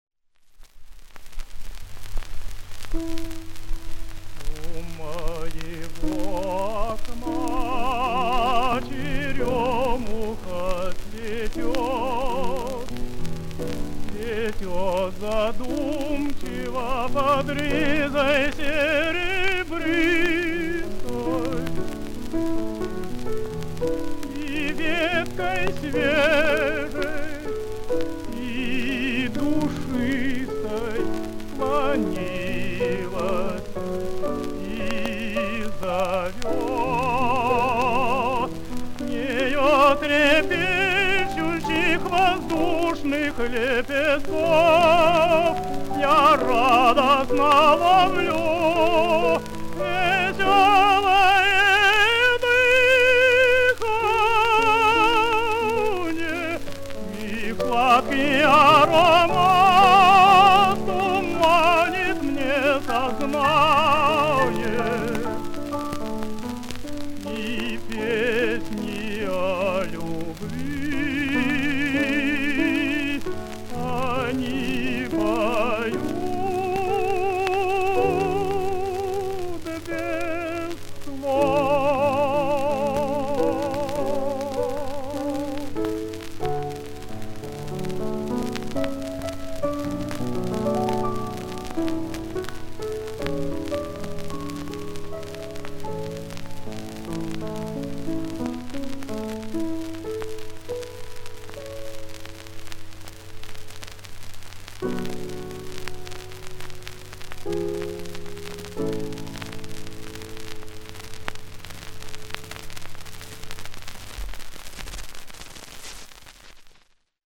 тенор
Романс «У моего окна».
Партия фортепиано